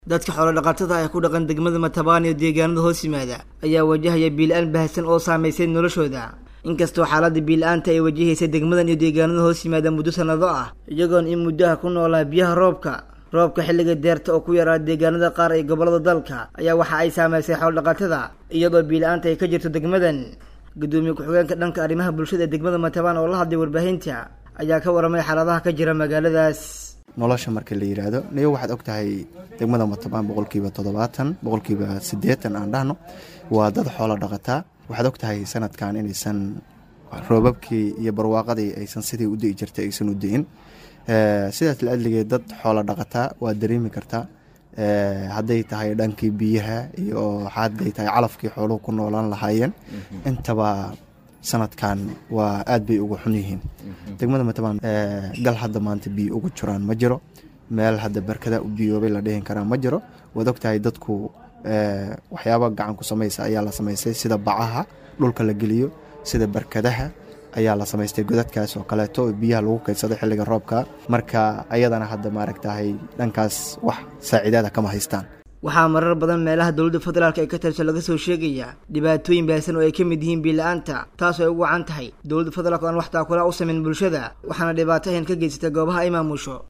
Gudoomiye ku xigeenka dhanka arrimaha bulshada ee degmada Matabaan ayaa ka warramay xaaladaha ka jira magaaladaas.